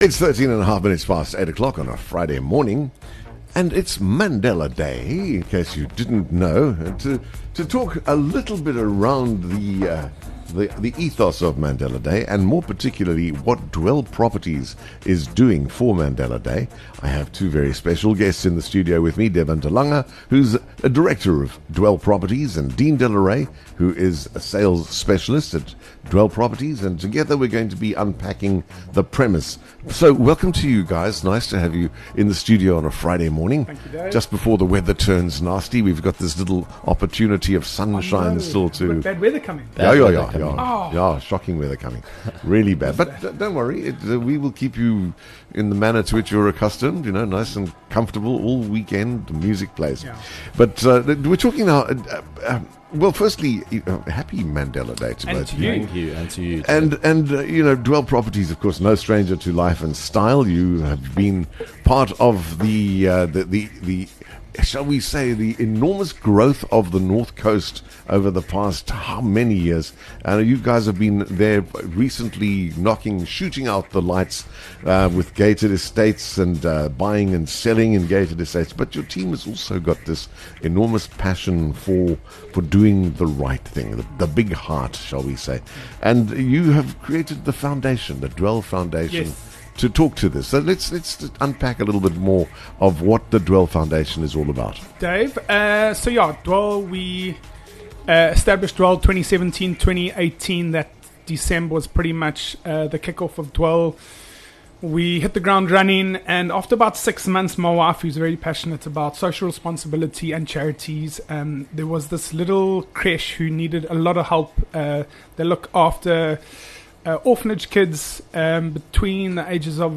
Broadcasting live from the heart of Ballito, The Morning Show serves up a curated mix of contemporary music and classic hits from across the decades, alongside interviews with tastemakers & influencers, plus a healthy dose of local news & views from the booming KZN North Coast.